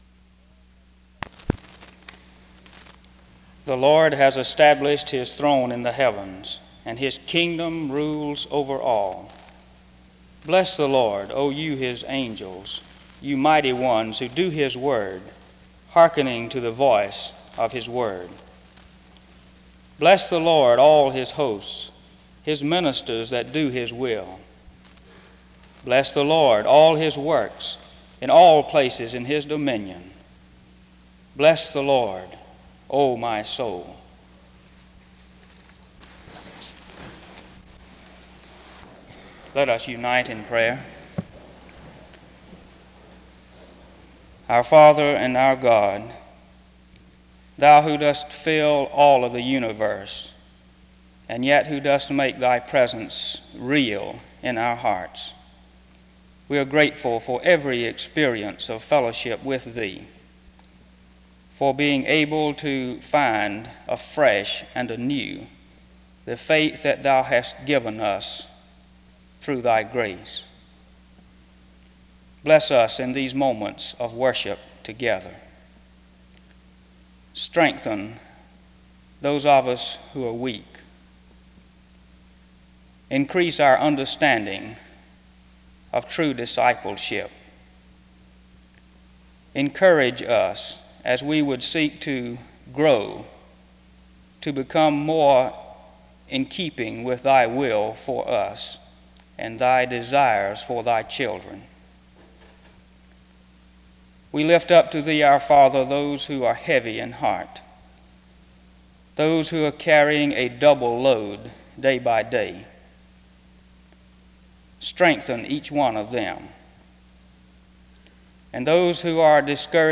SEBTS Chapel and Special Event Recordings SEBTS Chapel and Special Event Recordings